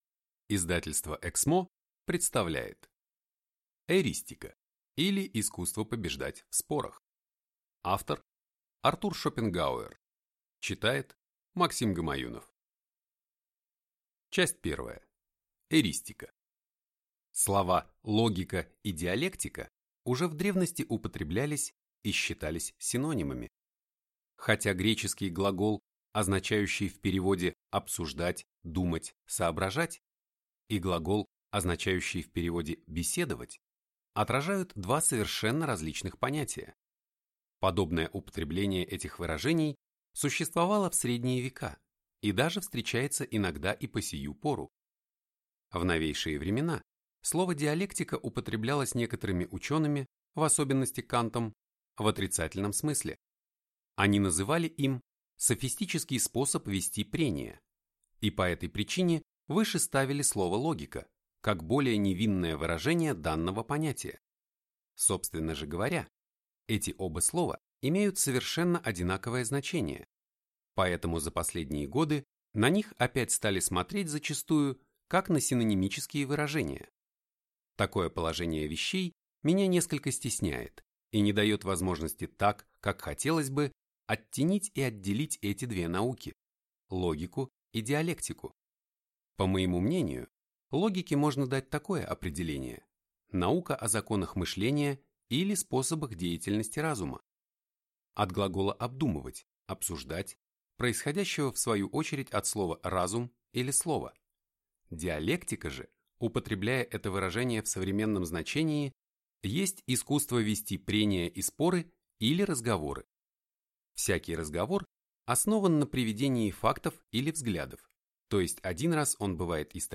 Аудиокнига Искусство побеждать в спорах | Библиотека аудиокниг